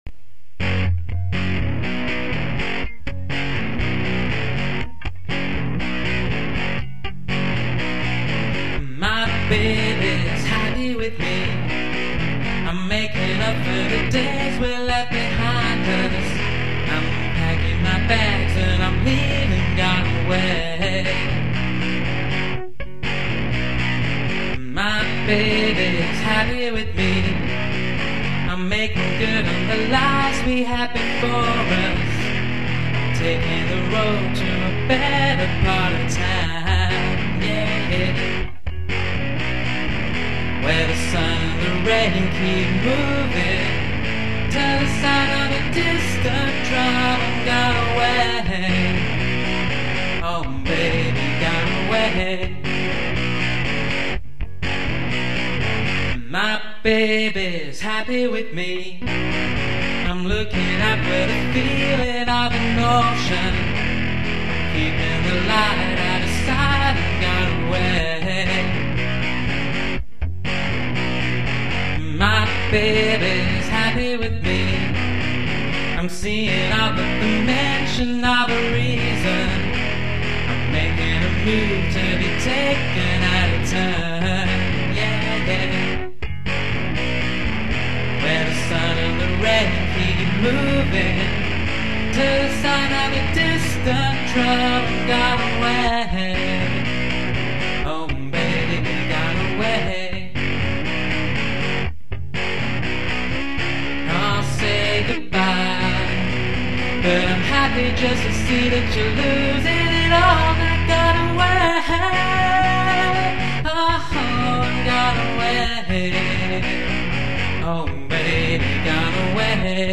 These are all lo-fi recordings made with a guitar, a microphone, and a lot of unfounded optimism.
I've got out the old electric guitar for this recording, so pump up the volume, and in the words of Smashie and Nicey, "Let's Rock!".